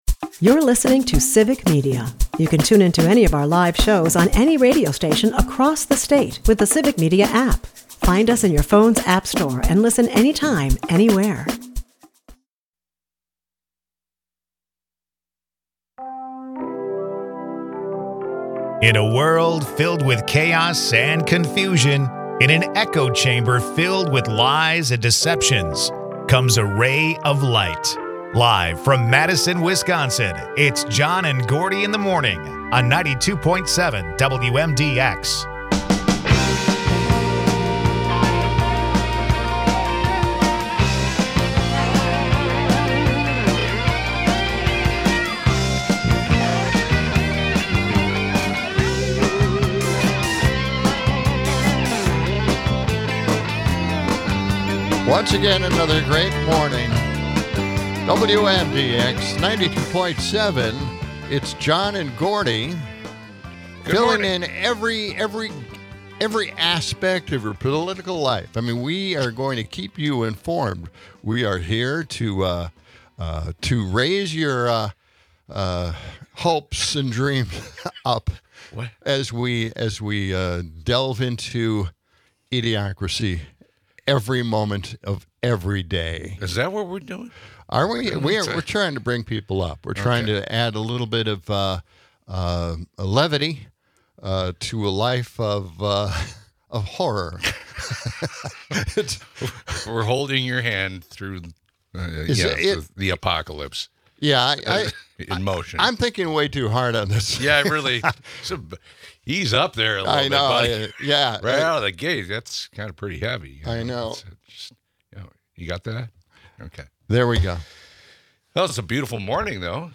a mix of weather updates, humor, and light-hearted banter about Memorial Day plans.